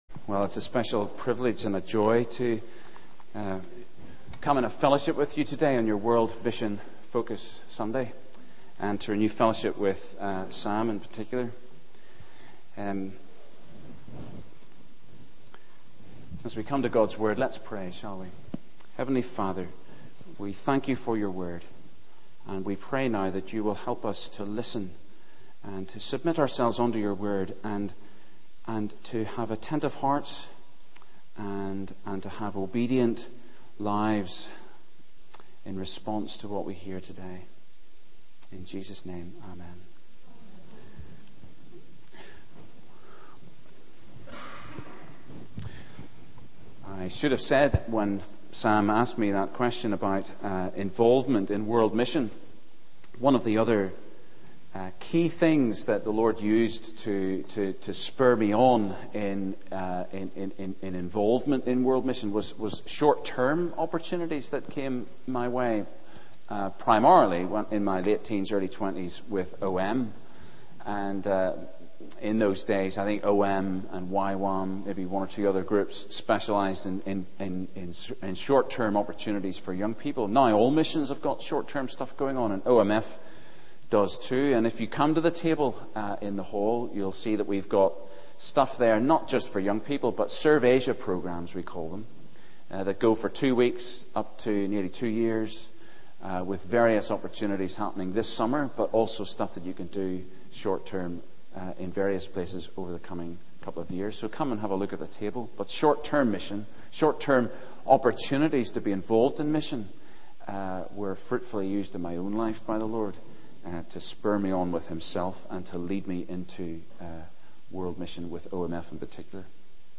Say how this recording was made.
Media for 9:15am Service